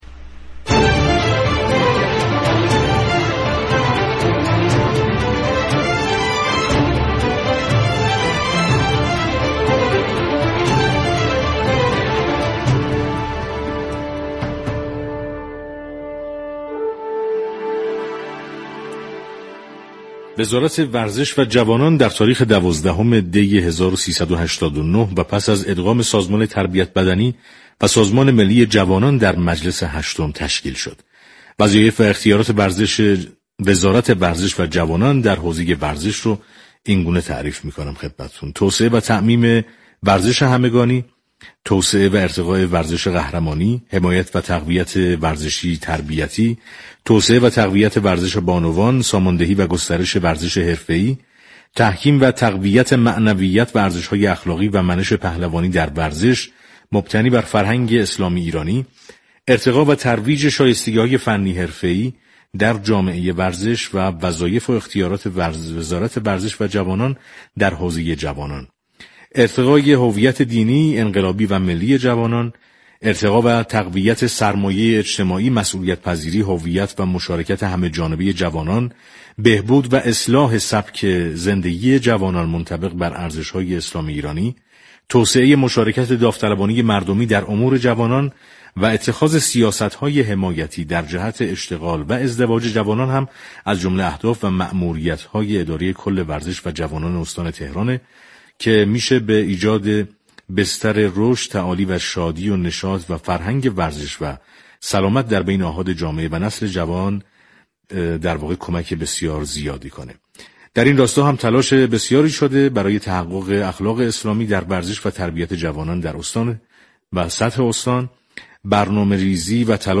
به گزارش پایگاه اطلاع رسانی رادیو تهران؛ علی جوادی مدیركل ورزش و جوانان استان تهران در گفت و گو با برنامه پل مدیریت 19 دی در توضیح وظایف اداره كل متبوع خود گفت: این اداره كل در دو حوزه ورزش و جوانان در لایه‌های مختلف سنی و جنسیتی فعالیت دارد.